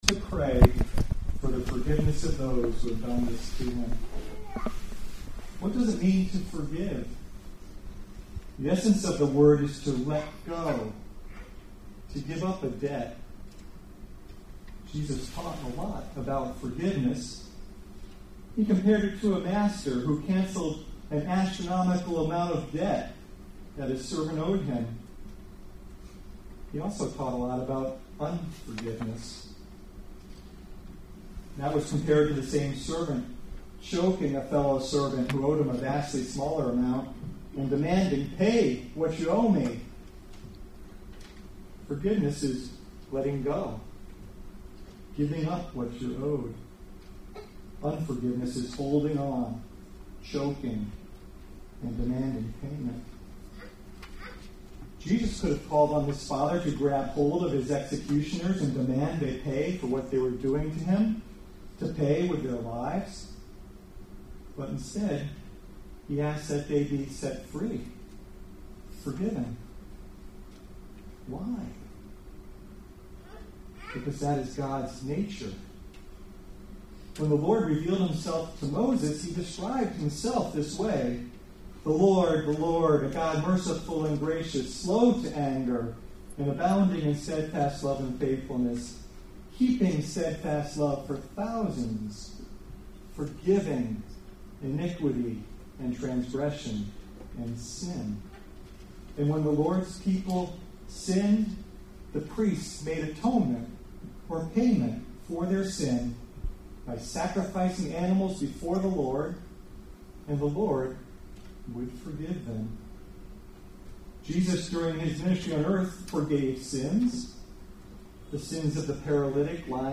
April 19, 2019 Special Services series Good Friday Service Save/Download this sermon Various Scriptures Other sermons from Various Scriptures Audio of our annual Good Friday Tenebrae Service. (recording begins shortly after the homily on the first saying of Jesus begins.)